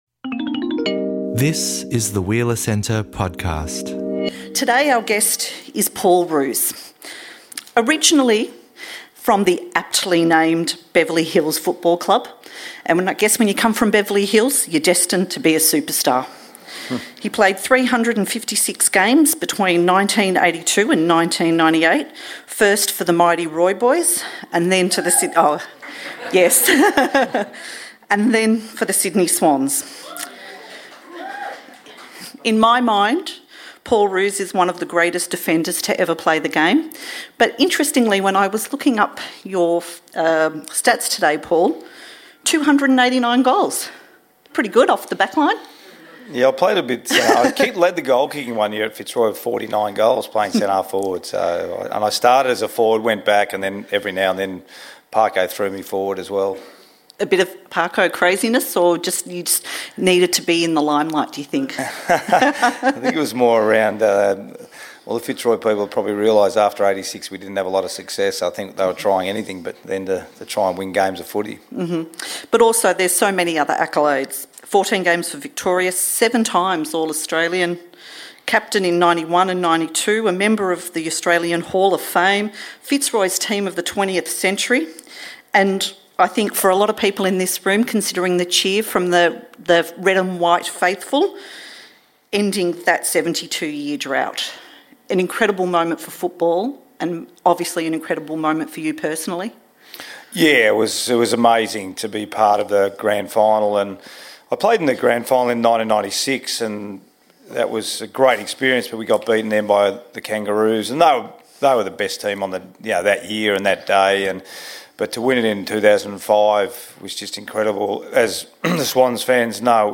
Paul Roos in Conversation